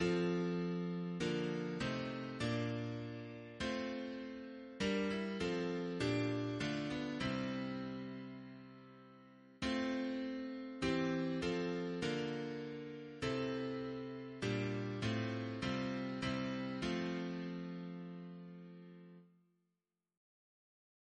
Double chant in F Composer: Robert Cooke (1768-1814), Organist of Westminster Abbey Note: after Old 124th Reference psalters: ACB: 5; H1982: S428; OCB: 11; PP/SNCB: 33